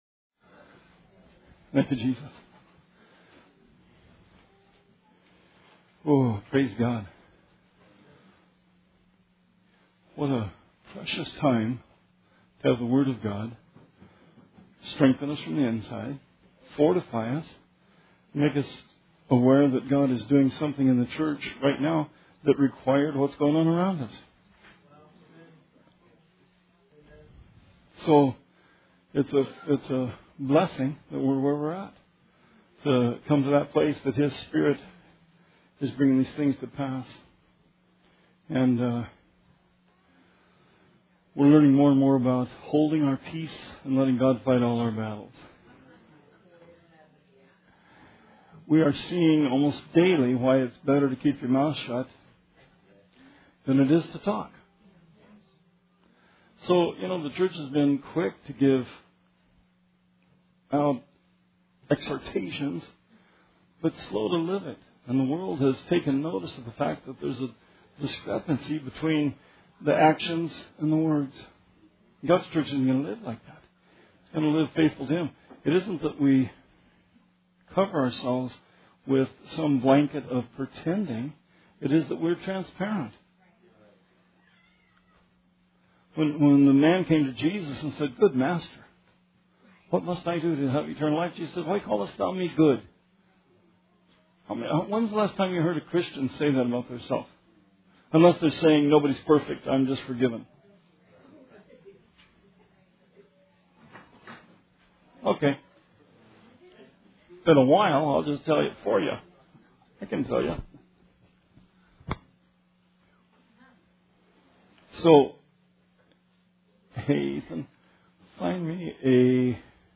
Bible Study 2/8/17